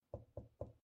Knocking 3 times